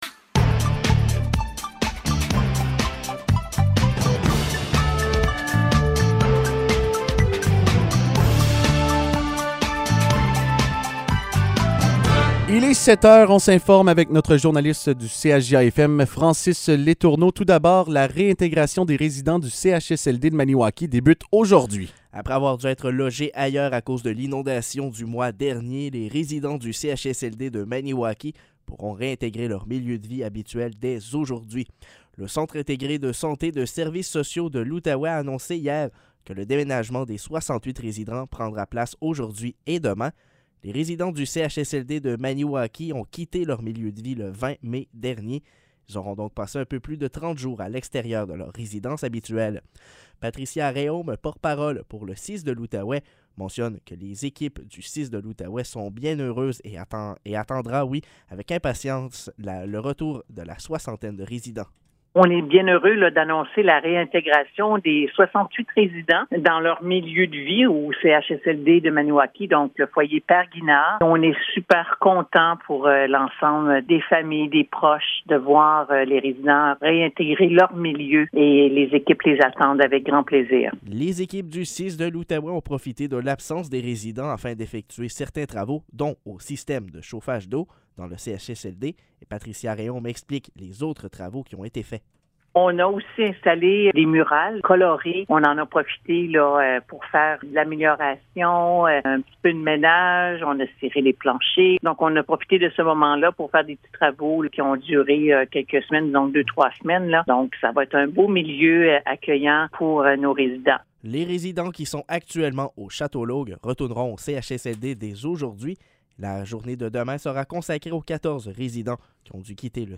Nouvelles locales - 21 juin 2022 - 7 h